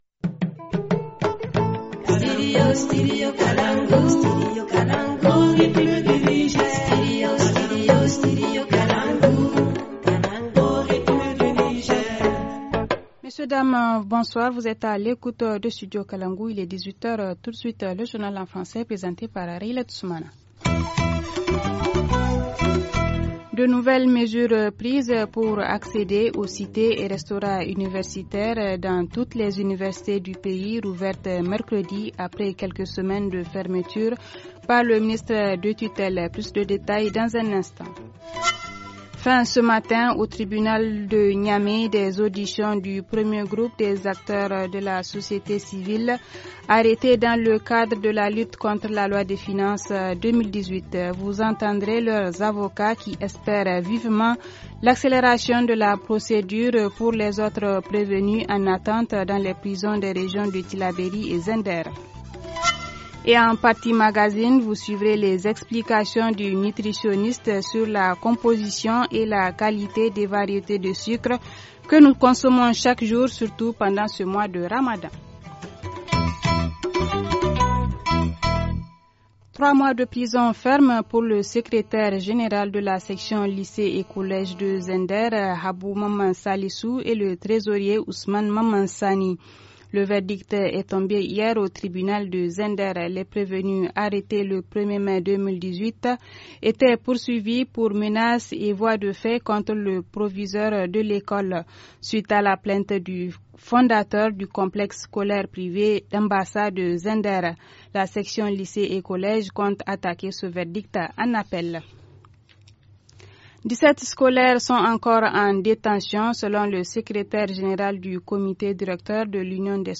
2- Fin ce matin, au tribunal de Niamey, des auditions du 1er groupe des acteurs de la société civile arrêtés dans le cadre de la lutte contre la loi de finances 2018. Vous entendrez leur avocat qui espère vivement l’accélération de la procédure pour les autres prévenus en attente dans les prisons des régions de Tillabéri et Zinder.